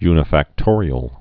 (ynə-făk-tôrē-əl)